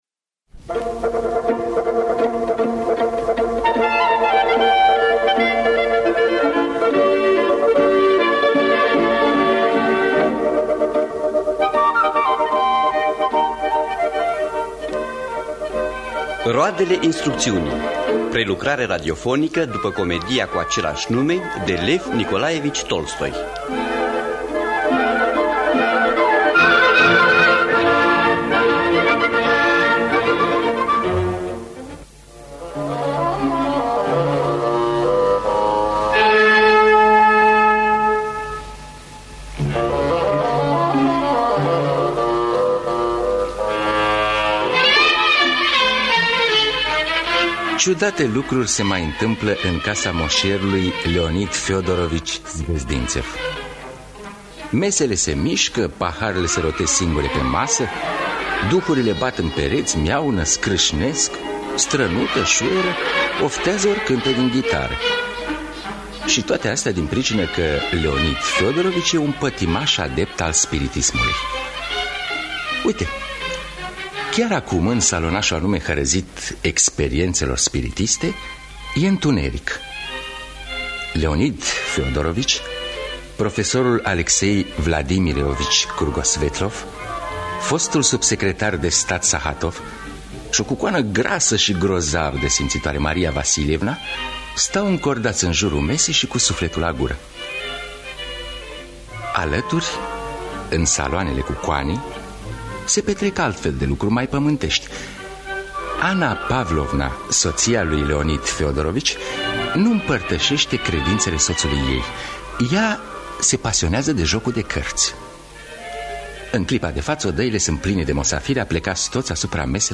Roadele instrucţiunii de Lev Nikolaievici Tolstoi – Teatru Radiofonic Online